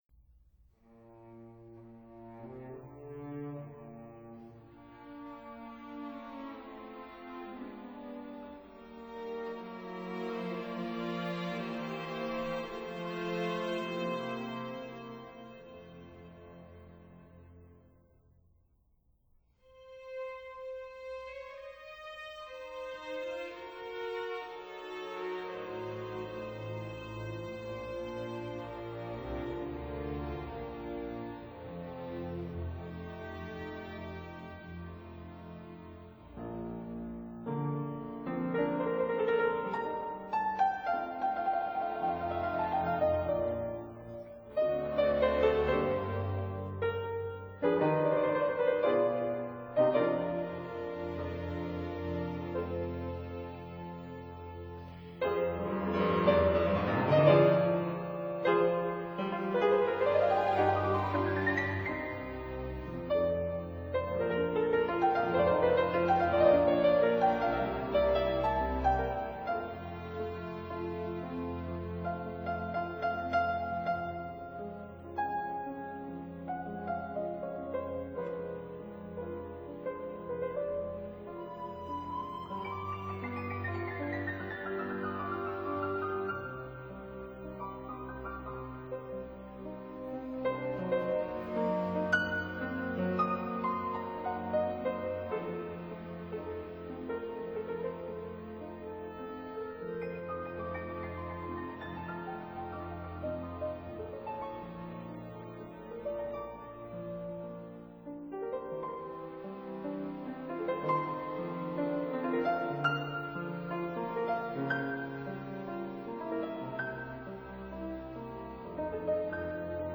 美國鋼琴家
London Érard Piano 1851